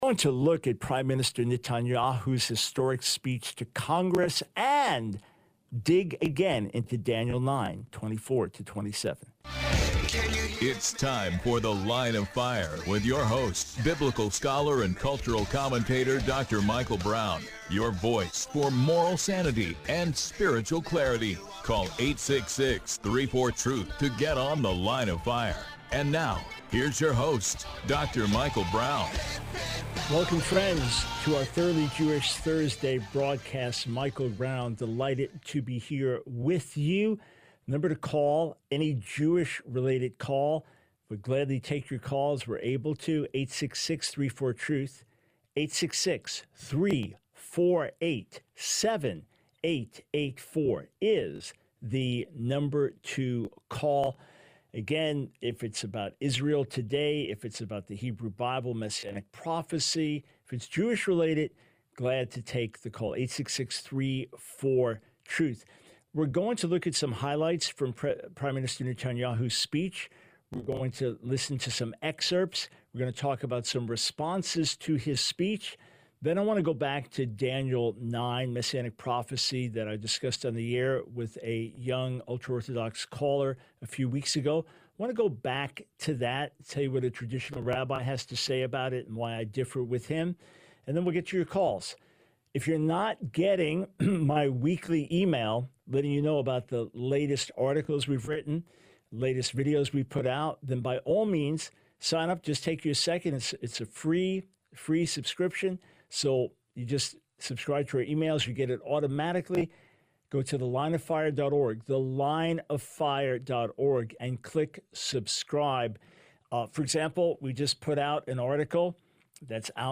The Line of Fire Radio Broadcast for 07/25/24.